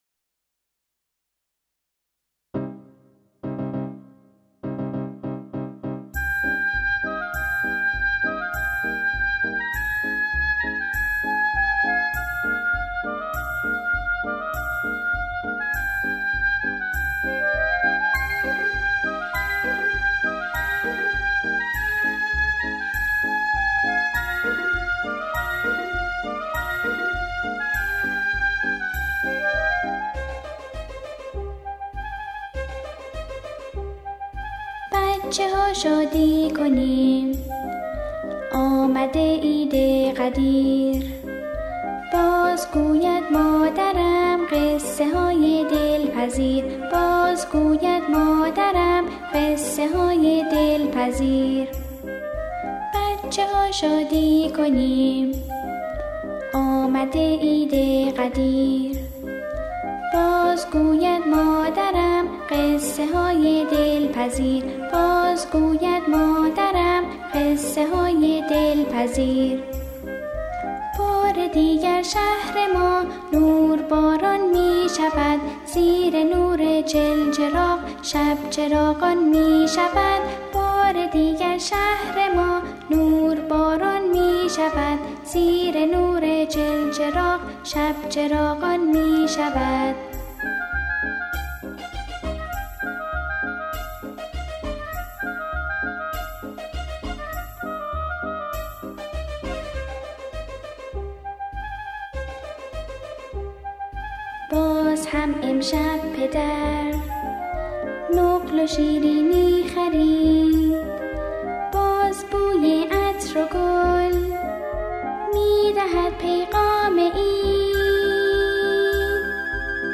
قطعه
این سرود بوسیله تکخوان ناشناس اجرا شده است.